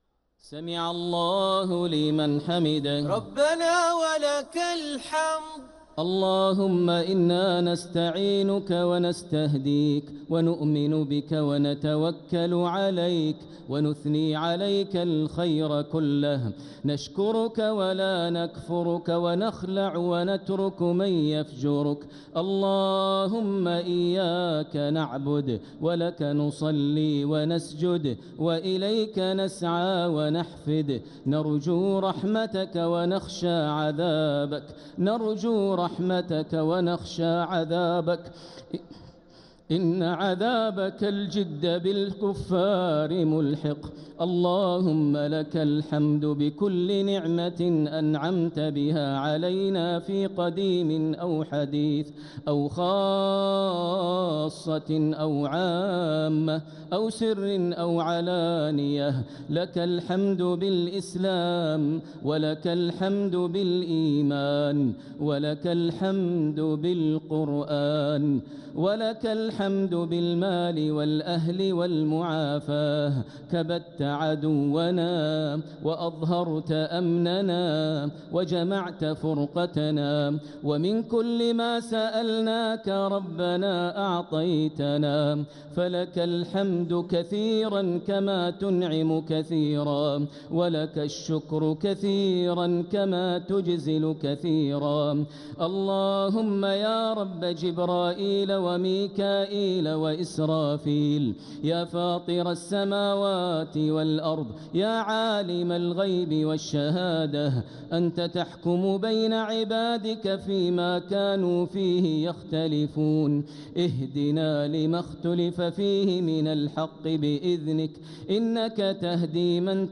دعاء القنوت ليلة 28 رمضان 1446هـ > تراويح 1446 هـ > التراويح - تلاوات ماهر المعيقلي